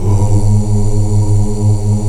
Index of /90_sSampleCDs/Roland LCDP11 Africa VOL-1/VOX_African Oos/VOX_African Oos